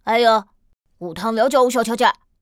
c01_4残疾小孩_2.wav